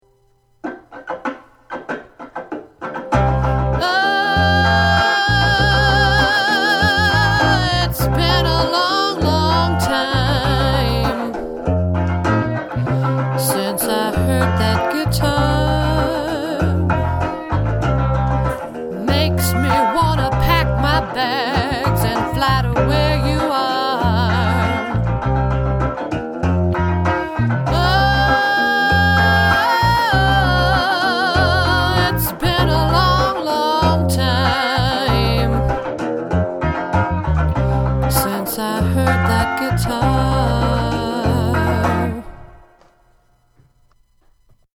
Now we put all 3 together and it's starting to sound like song!
PlayAlongBass&Vocal.mp3